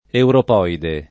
[ europ 0 ide ]